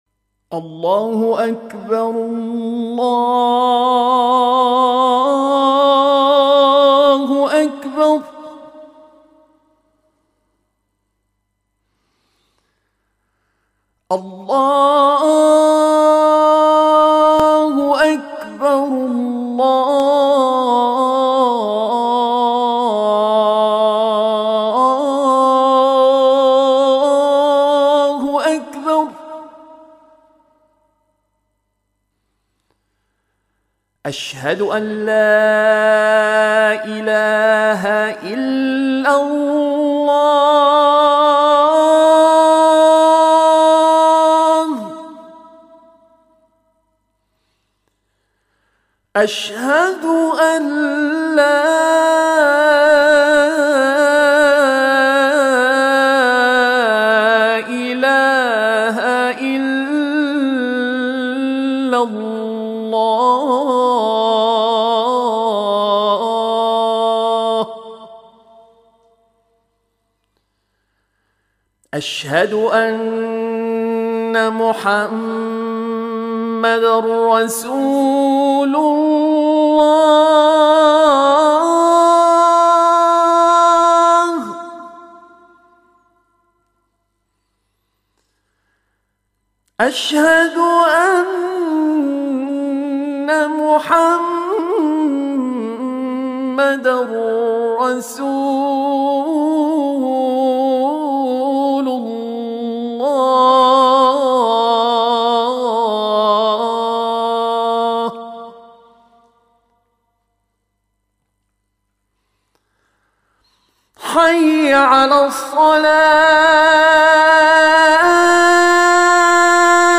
أذان رصد.